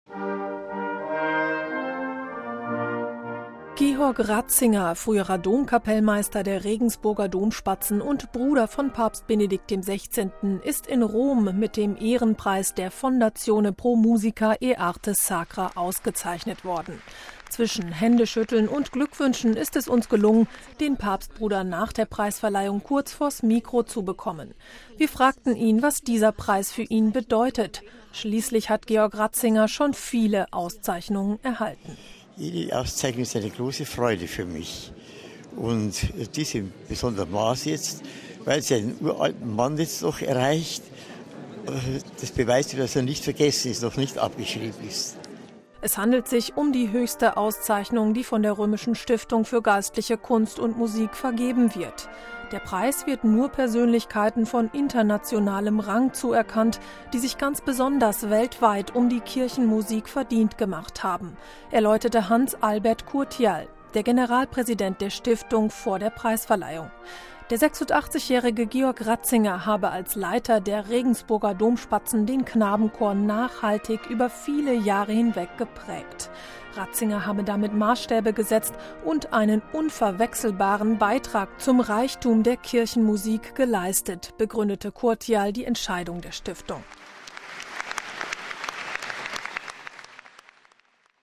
Zwischen Händeschütteln und Glückwünschen ist es uns gelungen, den Papstbruder nach der Preisverleihung kurz vors Mikro zu bekommen. Wir fragten ihn, was dieser Preis für ihn bedeutet – schließlich hat Georg Ratzinger schon viele Auszeichnungen erhalten.